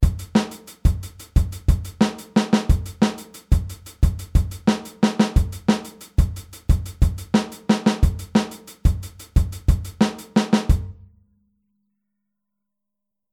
Aufteilung linke und rechte Hand auf HiHat und Snare
Groove21-16tel.mp3